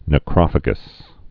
(nə-krŏfə-gəs, nĕ-)